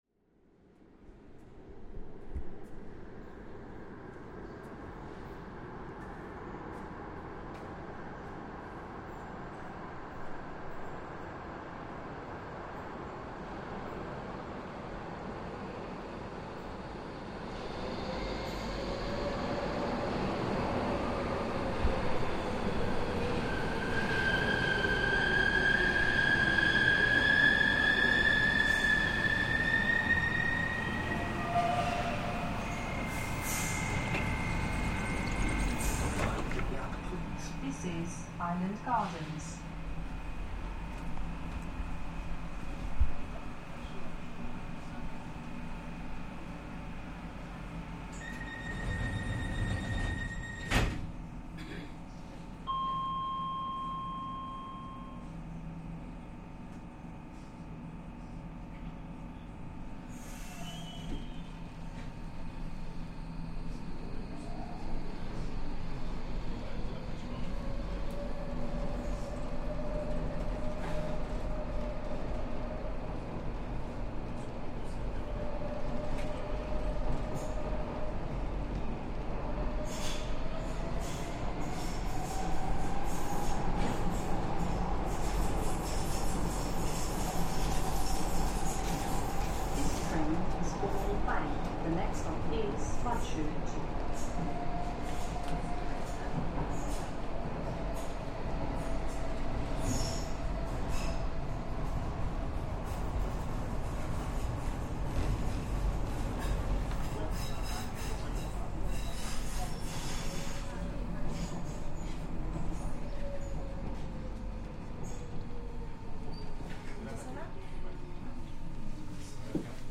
Aboard the Docklands Light Railway (DLR) line of the London Underground, travelling from Island Gardens to Westferry. Announcements, doors closing, the thrum of passengers sounds and the drone of train engines.